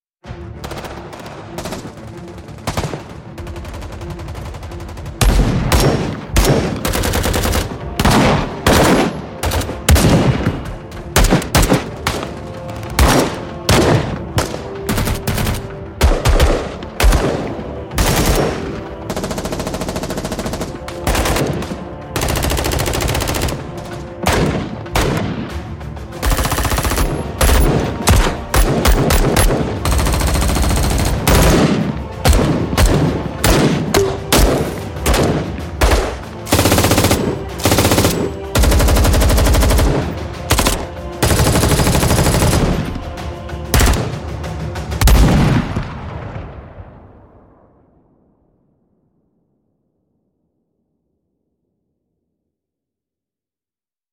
动手扳机，掌握大量高端枪声录音：25种不同的武器以18通道多轨录音的形式出现。
设计的突击武器
全自动武器（突击步枪，机枪，冲锋枪）具有单发，连发和全自动的声音，每种声音都有四种不同的设计风格 （远距离|重物|现代|关闭） ，可为您提供快速的声音方便选择您想要的场景。